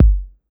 07_Kick_20_SP.wav